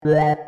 Sequencial Circuits - Prophet 600 28